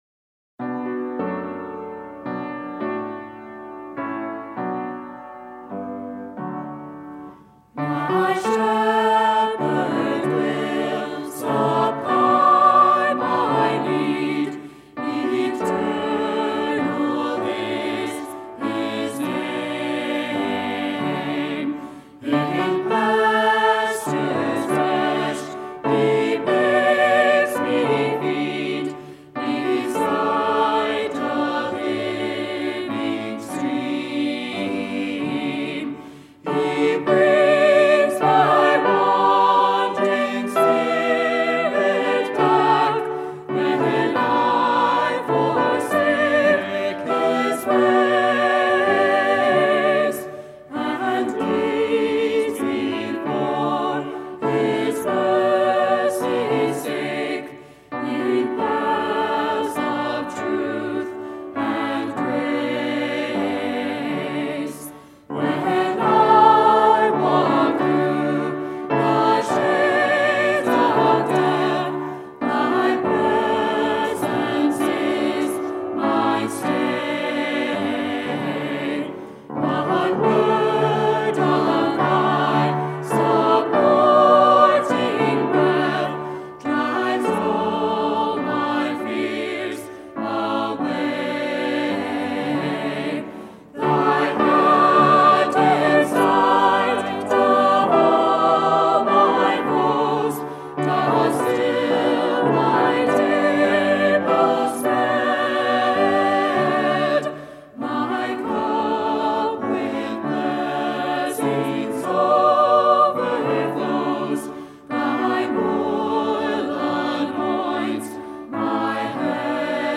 With Vocals